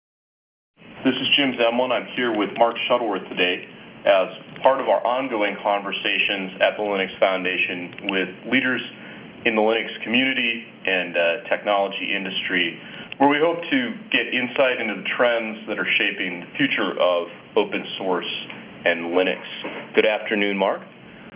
As reference speech fragment the part of Mark Shuttleworth's interview was given.
Then we pass this speech sample through wav2rtp with filter "independent packet losses" turned on and compare source and degraded file with pesqmain utility.
Table 1: Independent network losses influation on the output speech quality (G.729u)